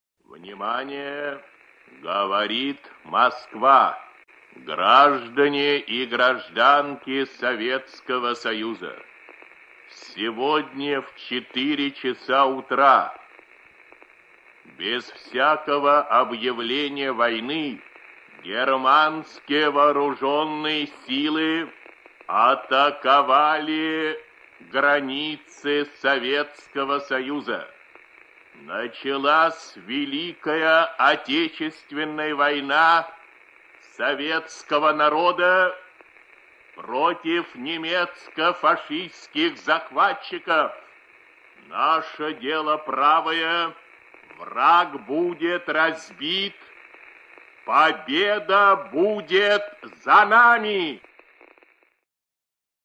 Текст читает диктор Всесоюзного радио, народный артист РСФСР и СССР Левитан Юрий Борисович